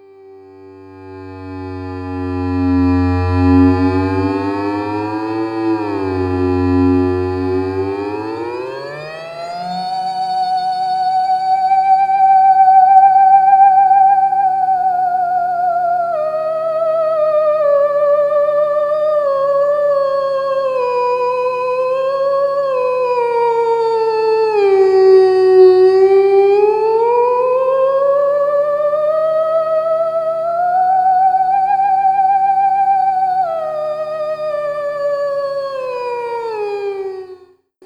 Here is an analog theremin sound I call "
(an acoustic trick, don't focus on the noise in the recording)